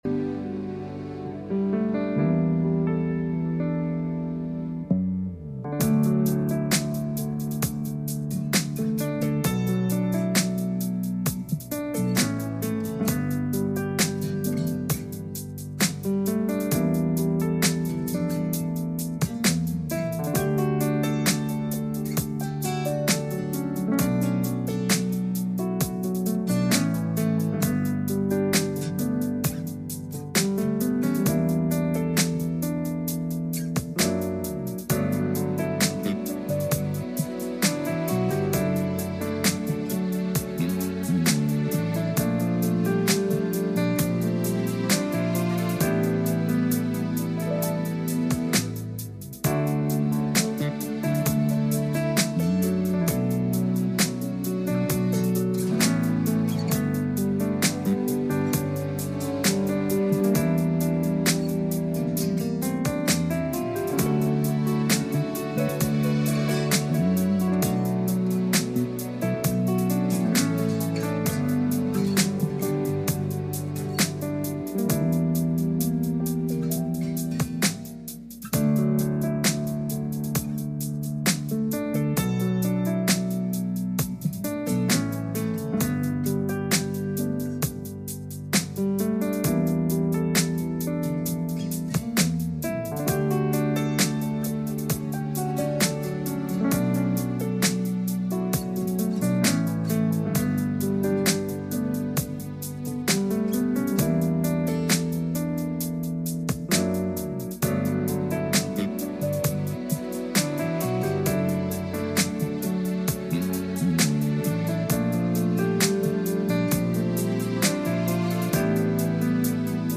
Exodus 4:1-9 Service Type: Sunday Morning « The Impact Of Eutychus